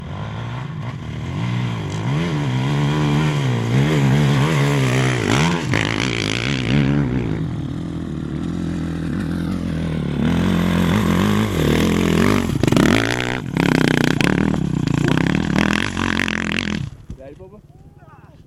越野车 " 摩托车 越野车 越野车 接近爬坡 斗争，停止3
描述：摩托车越野车越野摩托车越野爬山，停止